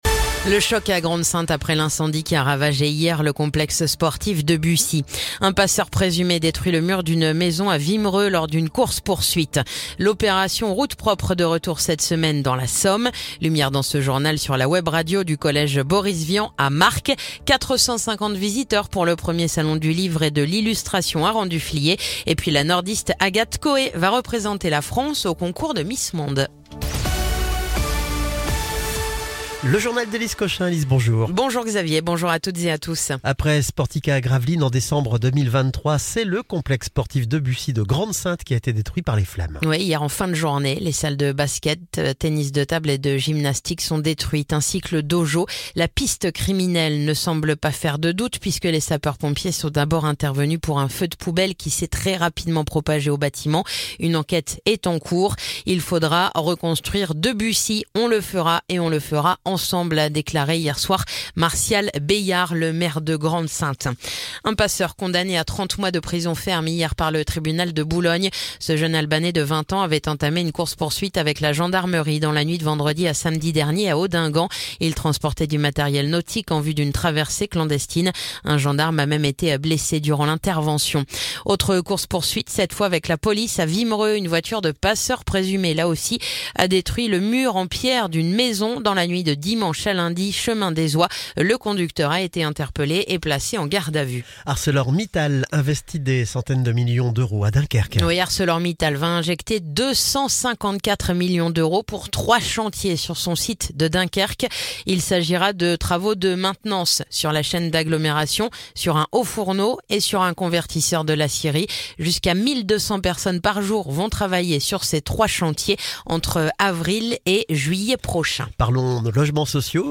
Le journal du mardi 11 mars